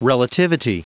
Prononciation du mot relativity en anglais (fichier audio)